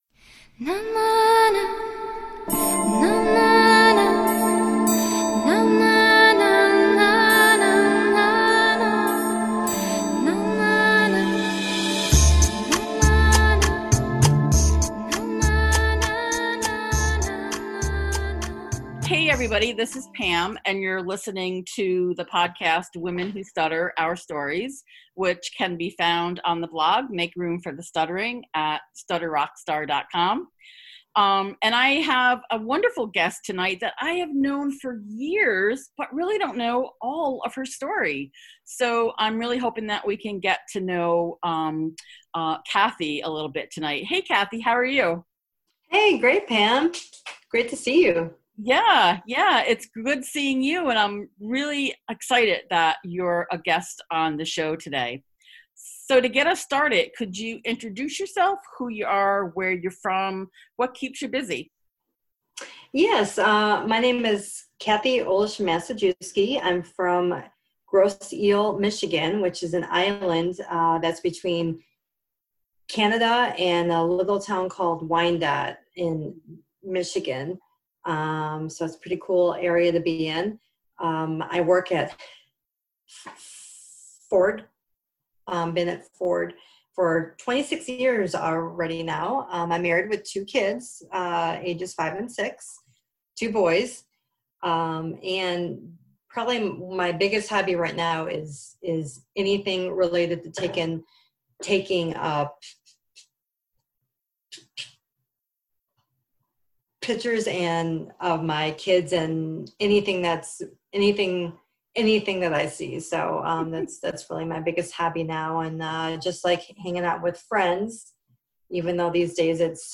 We talk about a number of things in this eye opening and inspiring conversation about covert stuttering.